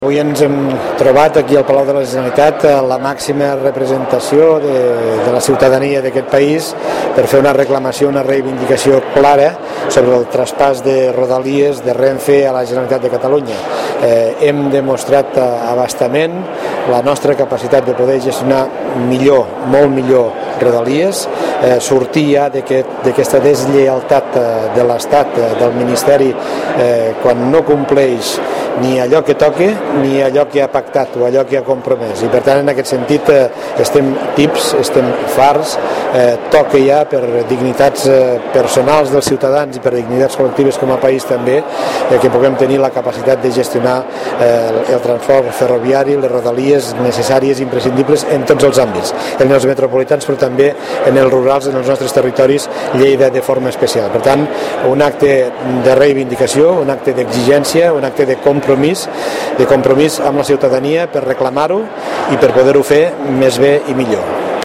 El president de la Diputació de Lleida a l’Acte per al traspàs de rodalies a la Generalitat, que s’ha celebrat aquest dimecres al Palau de la Generalitat
Re--e_acte_traspas_rodalies.mp3